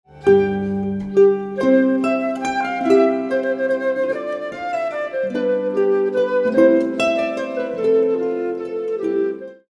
flute and harp